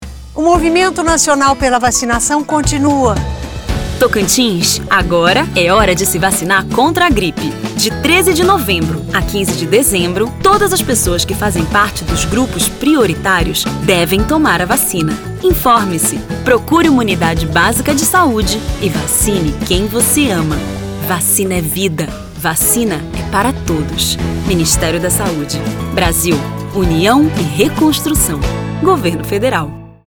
Tocantins: Spot - Vacinação Contra a Gripe em Tocantins - 30seg .mp3